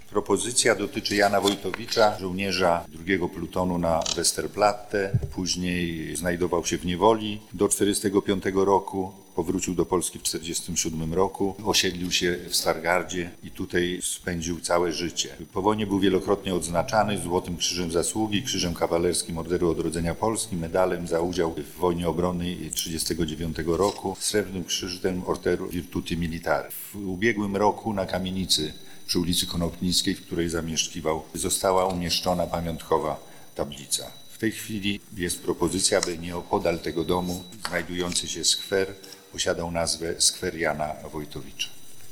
Opowiada zastępca prezydenta Piotr Mync.